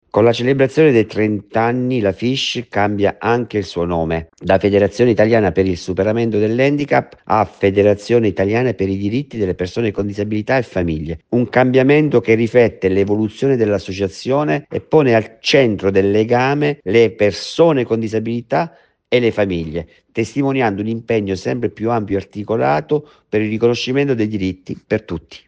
Calcio in ostaggio – Amnesty international denuncia la situazione dei diritti umani in Arabia Saudita dove si sta giocando la Supercoppa italiana. Il servizio